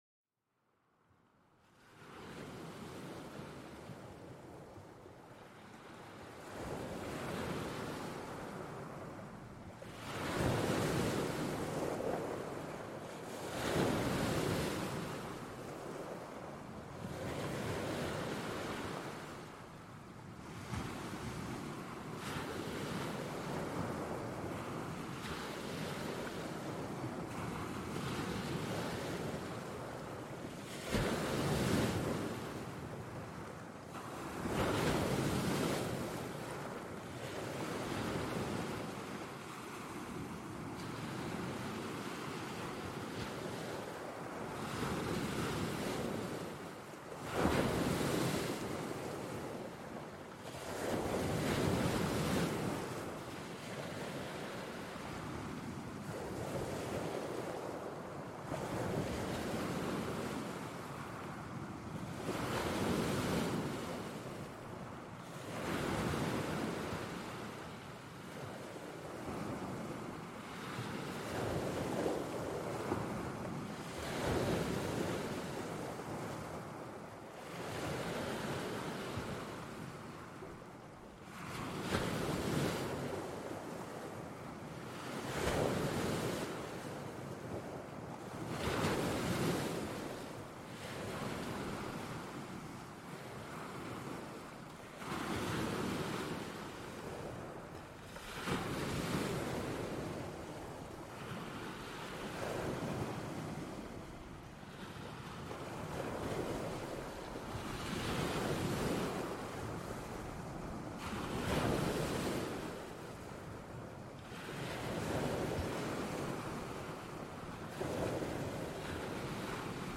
Experimenta el poder calmante de las olas chocando contra las rocas en este episodio único. Cada oleaje te acerca más a la tranquilidad, envolviéndote en un mundo de calma y serenidad. Perfecto para una escapada auditiva, este sonido natural es ideal para relajarse o dormir.Este podcast explora los sonidos de la naturaleza, desde la lluvia hasta las olas, creando un ambiente propicio para la relajación y el sueño.